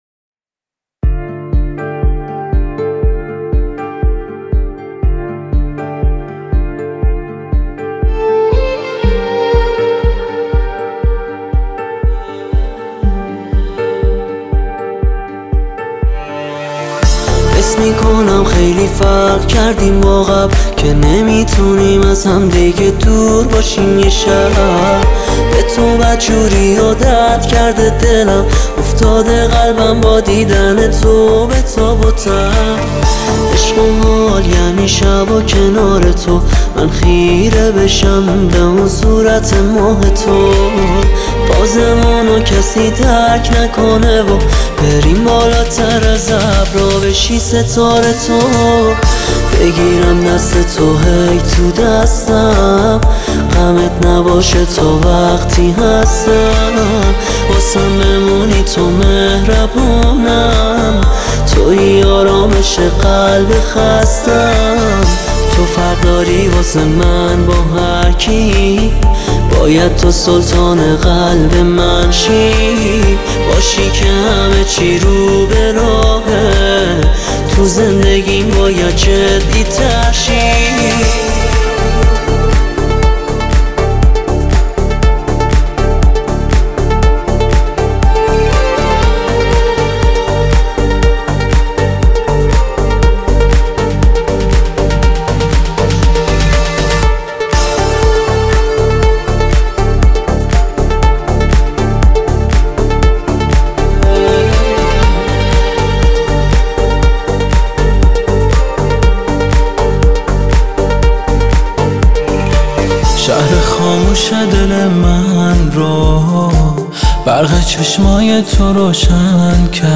موزیک شاد